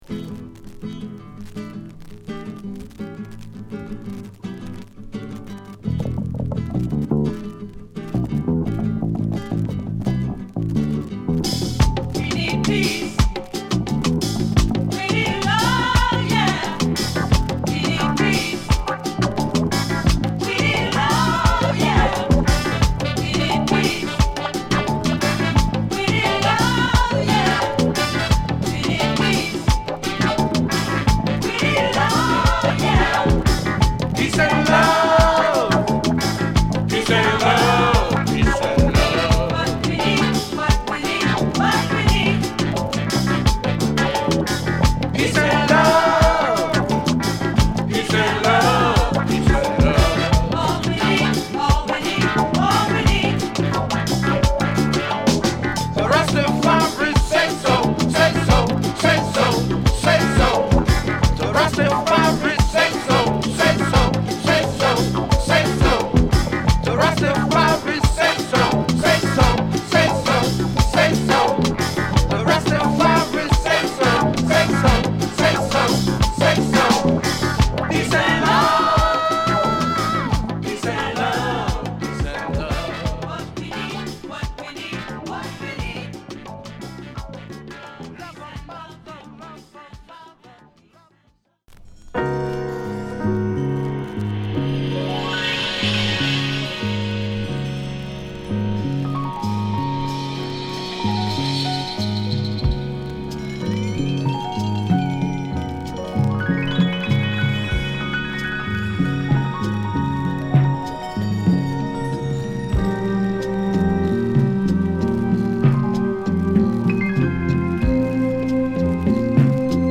チャカポコしたジャズファンクトラックに男女混声でファンキーに歌うA
美しいピアノが緩やかなシンセやパーカッションと絡む激メロウチューン